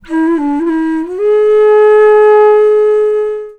FLUTE-B03 -L.wav